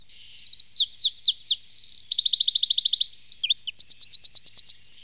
Troglodyte mignon
troglodyte.mp3